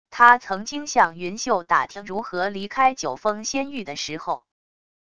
他曾经向云袖打听如何离开九峰仙域的时候wav音频生成系统WAV Audio Player